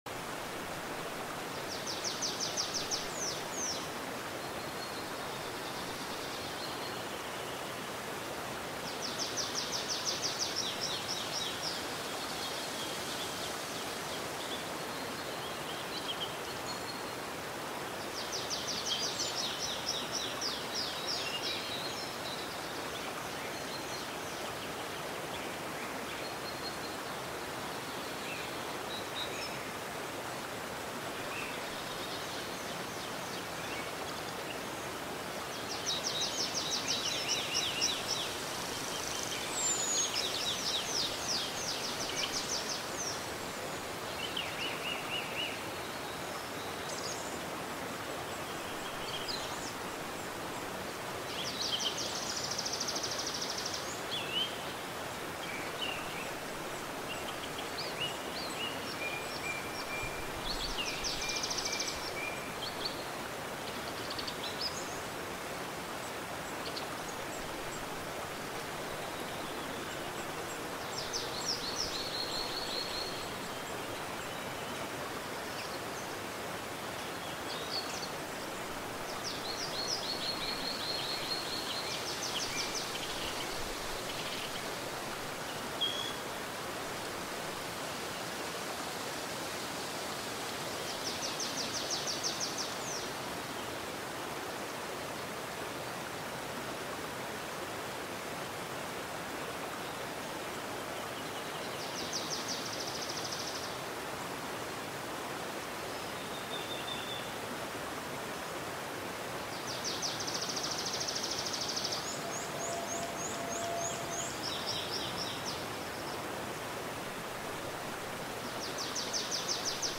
دانلود آهنگ رودخانه و پرندگان 15 دقیقه از افکت صوتی طبیعت و محیط
دانلود صدای رودخانه و پرندگان 15 دقیقه از ساعد نیوز با لینک مستقیم و کیفیت بالا